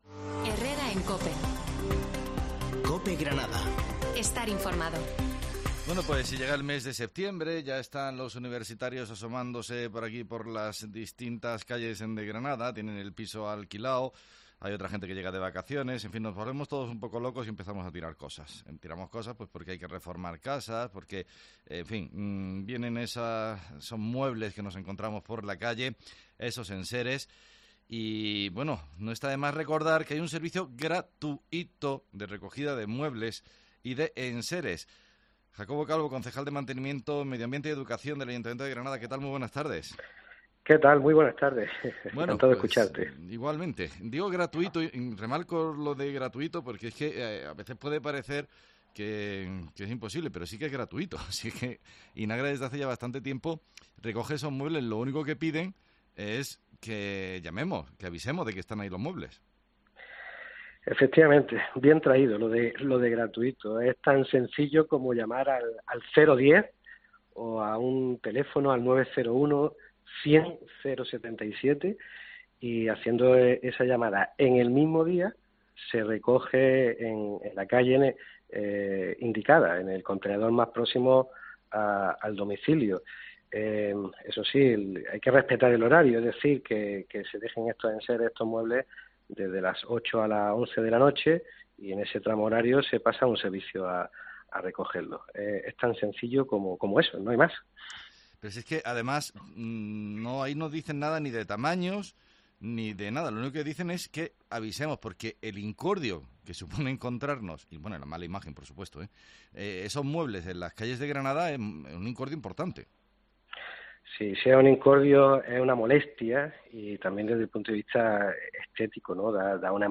Hablamos con el concejal de medioambiente de la recogida gratuita de muebles